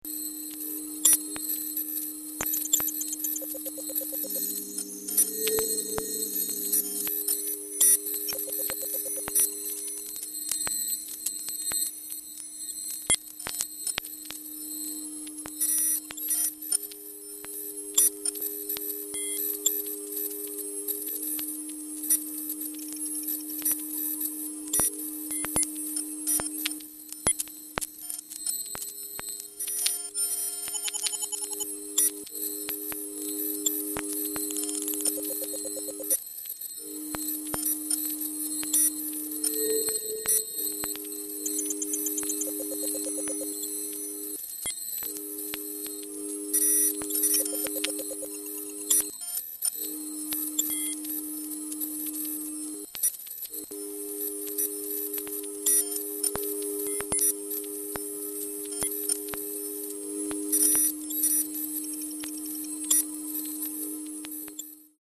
sound art
Soundscapes
Noise music